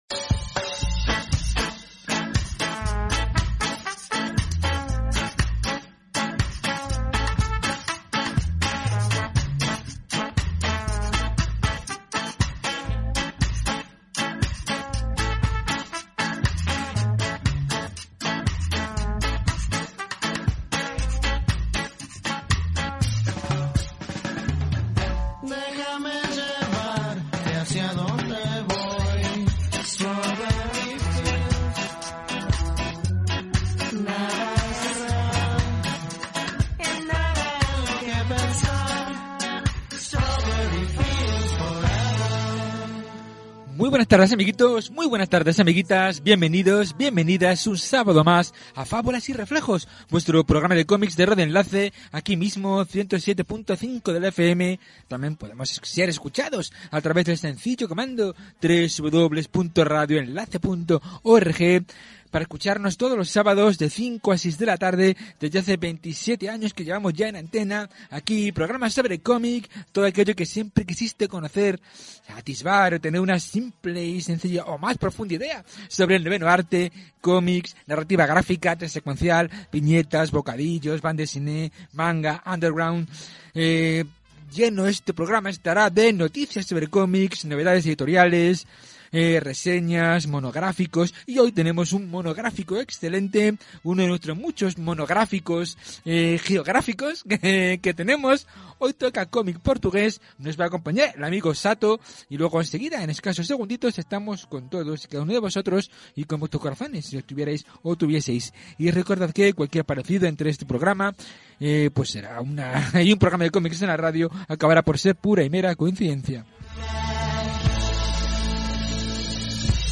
Es por ello que encontrarás un programa cargado de títulos, humor, cine, personajes, berenjenas y todo siempre acompañado de la mejor música posible.